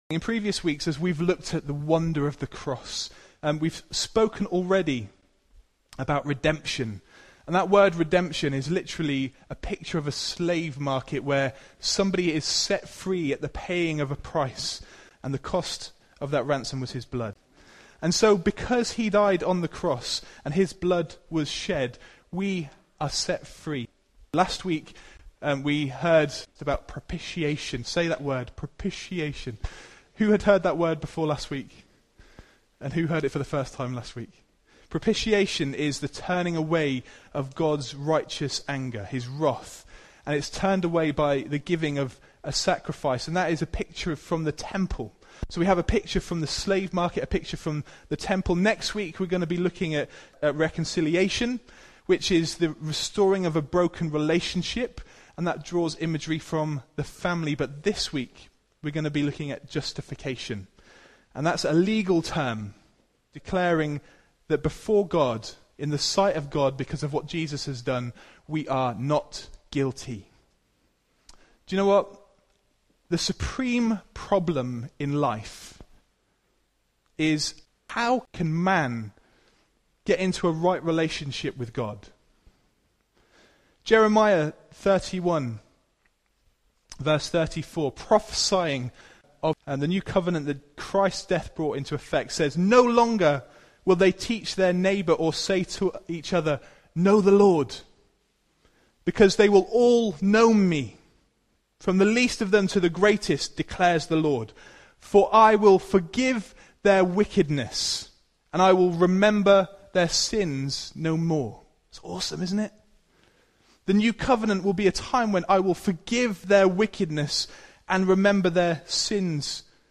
Mar 22, 2015 The Cross – Justification (Hope South) MP3 SUBSCRIBE on iTunes(Podcast) Notes Sermons in this Series Looking at another aspect of the cross - the legal declaration of "Not Guilty!" for all those who are in Christ.